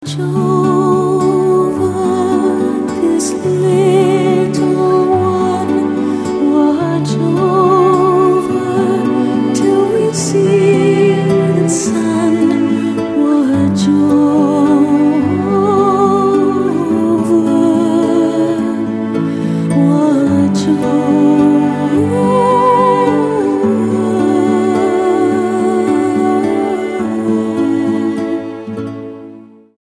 STYLE: Celtic
traditional Celtic instrumentation with soothing lullabies